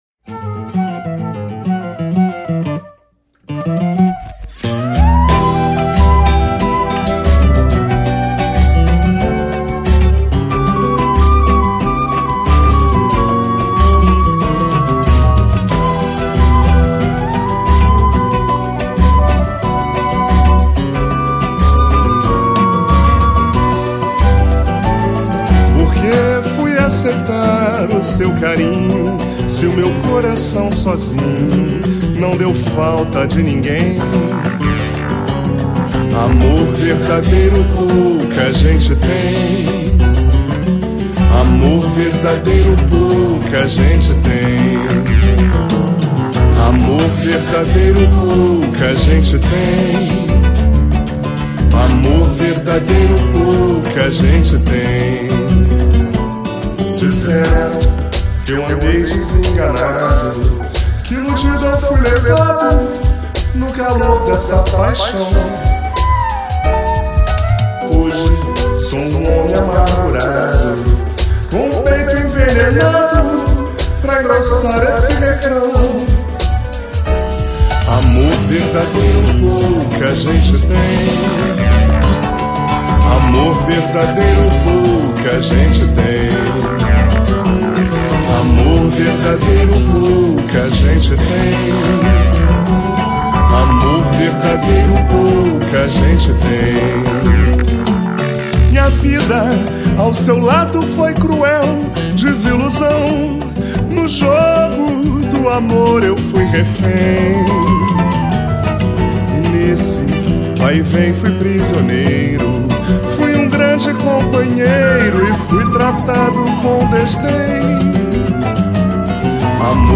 Samba-Funk samba carioca